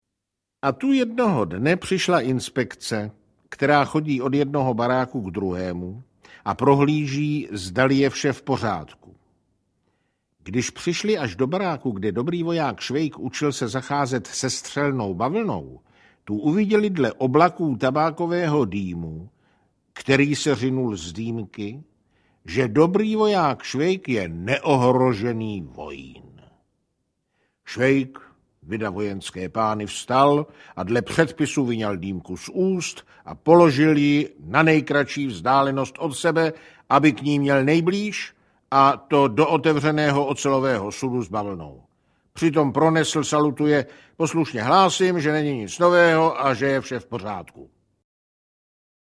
To nejlepší z české humoresky audiokniha
Ukázka z knihy